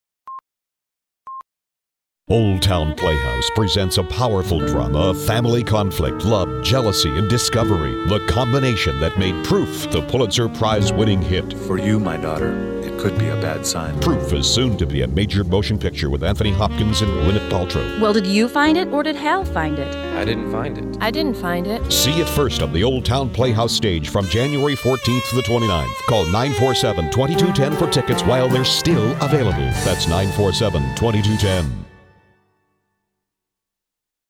TV Spot